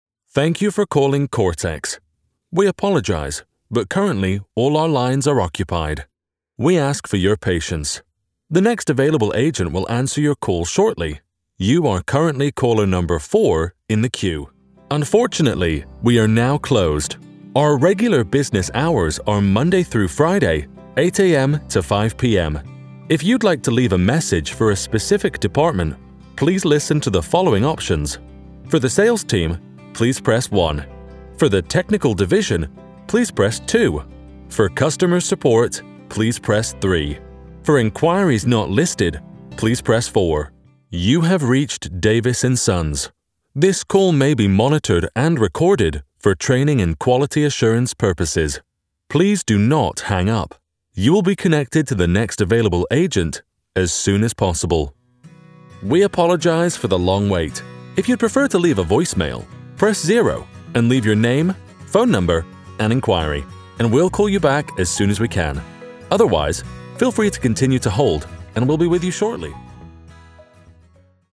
Comercial, Profundo, Cálida, Seguro, Empresarial
Telefonía